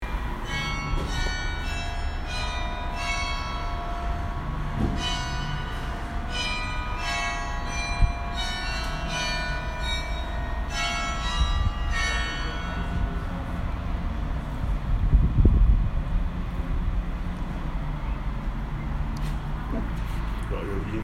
Hamburg clock chime